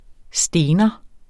Udtale [ ˈsdeːnʌ ]